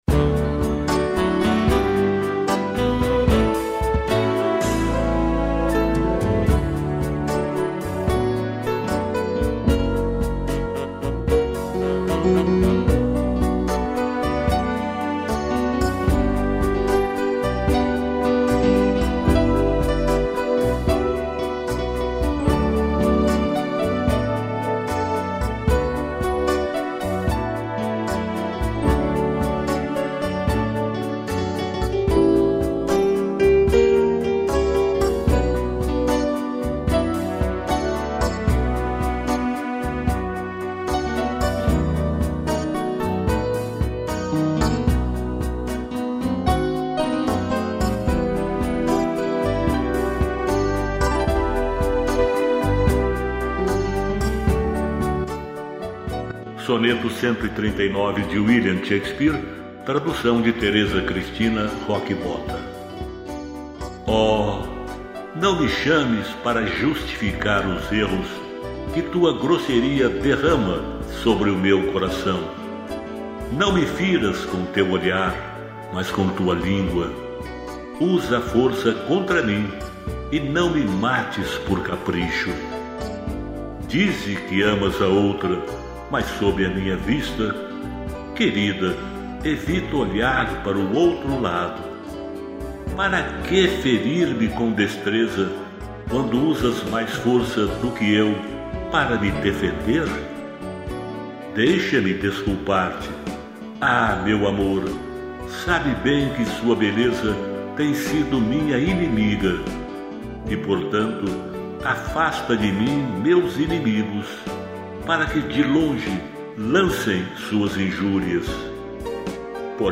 piano e tutti